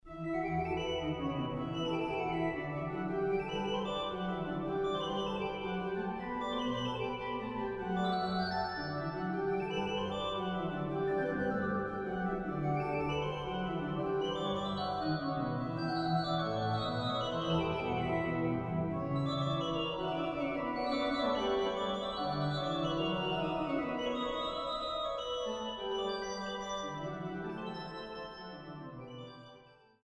Stellwagen-Orgel
Große Orgel